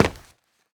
scpcb-godot/SFX/Step/Run7.ogg at 8f5d2fcf9fe621baf3dc75e4253f63b56f8fd64b